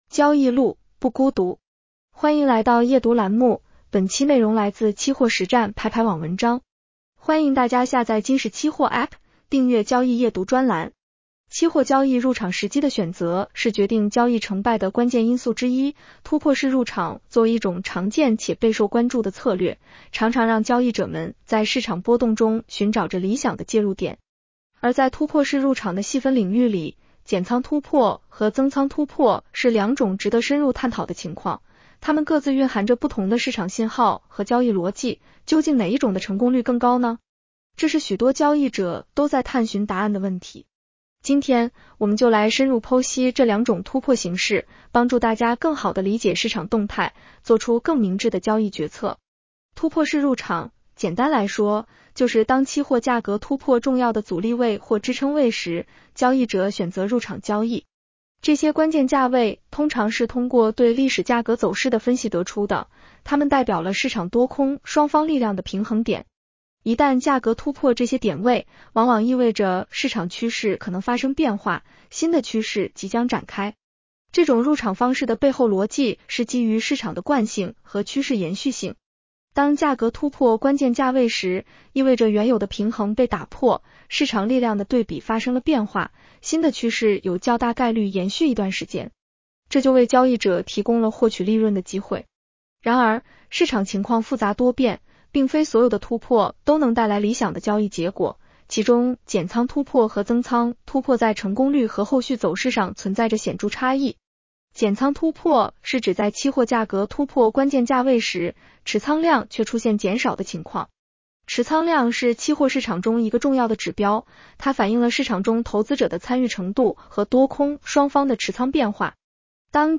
女声普通话版 下载mp3 期货交易入场时机的选择是决定交易成败的关键因素之一，突破式入场作为一种常见且备受关注的策略，常常让交易者们在市场波动中寻找着理想的介入点。